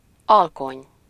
Ääntäminen
Ääntäminen US
IPA : /ˈsʌnˌsɛt/